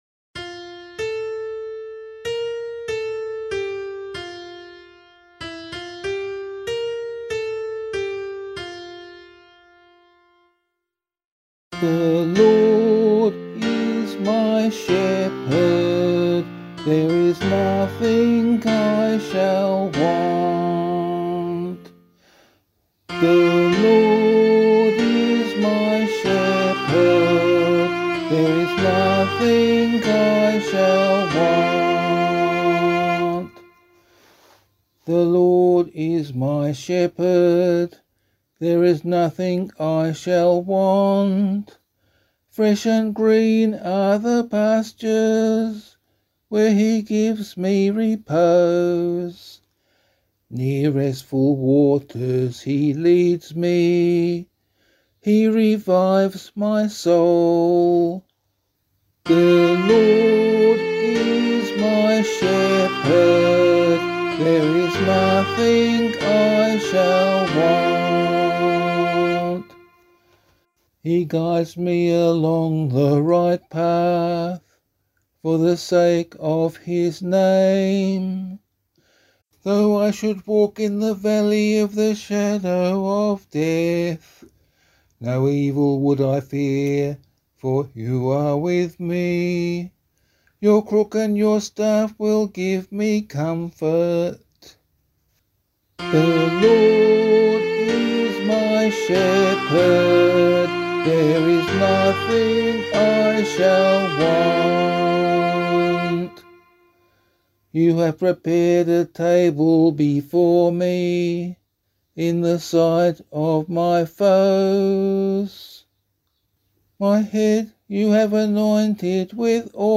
016 Lent 4 Psalm A [APC - LiturgyShare + Meinrad 6] - vocal.mp3